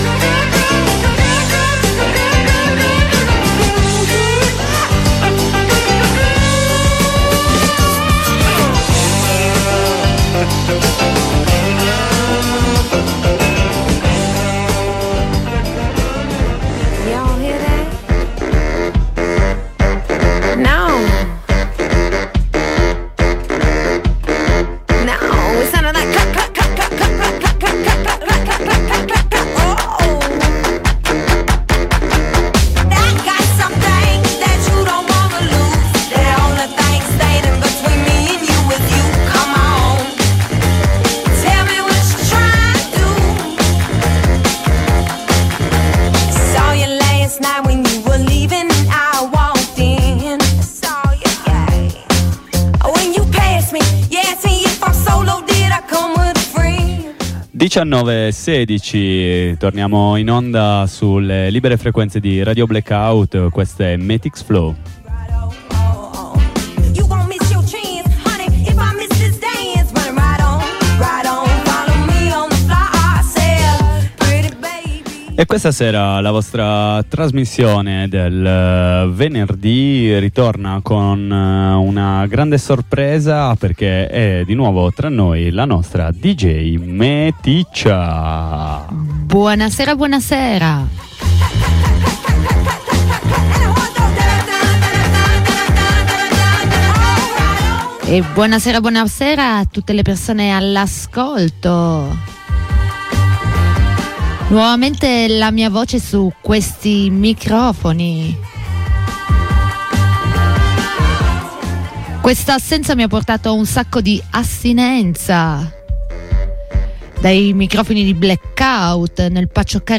Qua e là abbiamo anche inserito la lettura di qualche articolo di approfondimento sulla Palestina.